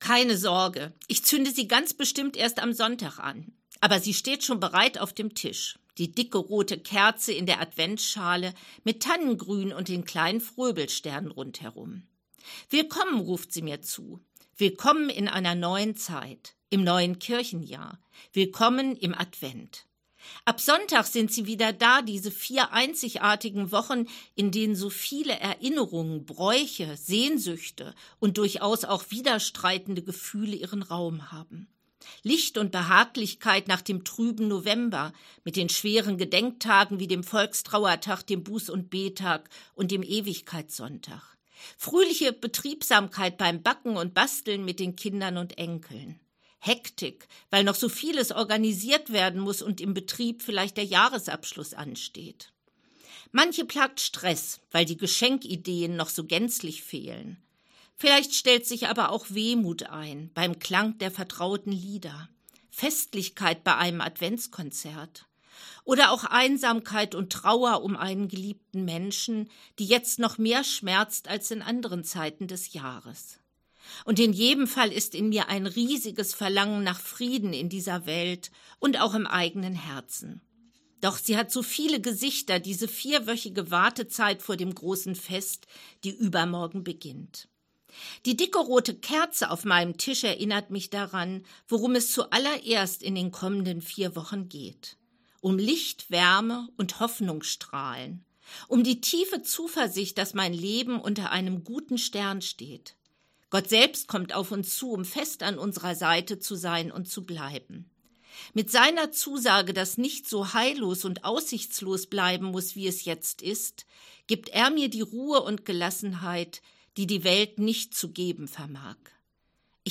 Radioandacht vom 29. November